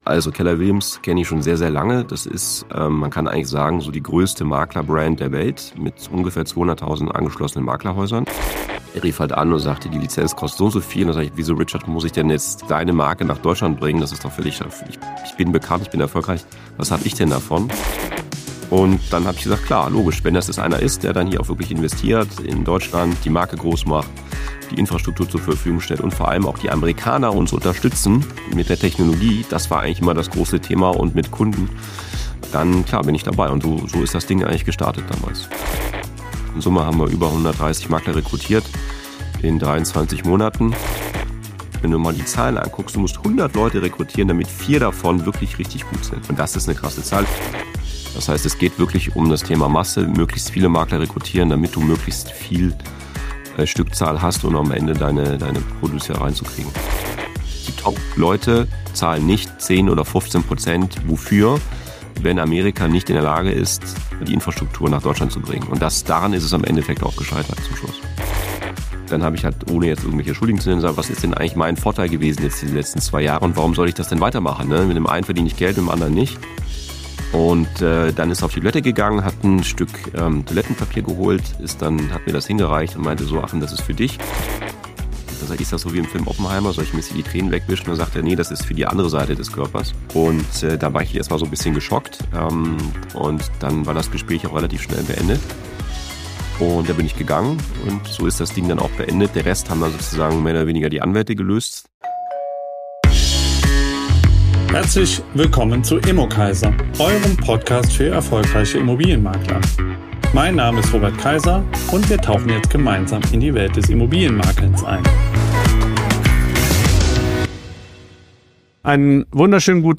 Ein Gespräch über Skalierung, kulturelle Unterschiede, Recruiting-Realitäten, Franchising im deutschen Markt – und die Frage, was wirklich zählt: das System oder der Service am Kunden?